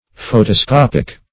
\Pho`to*scop"ic\